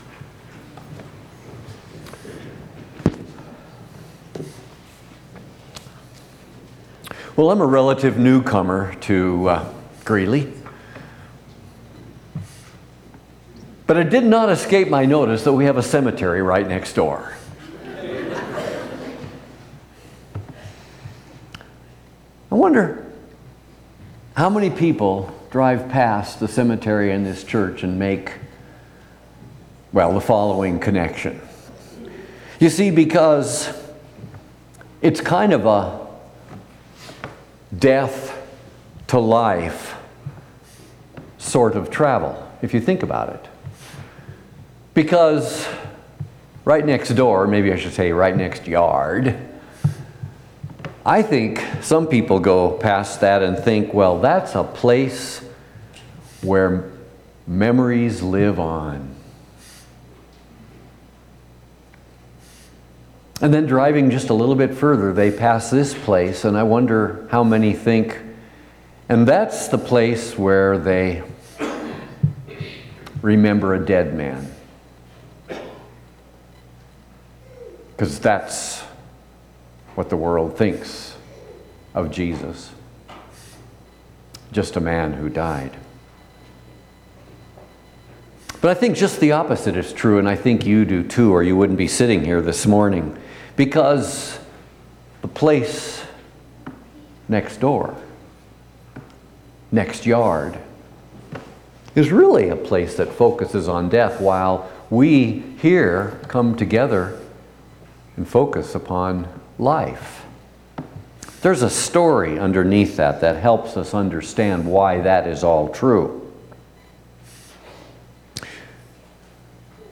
Last Sunday of the Church Year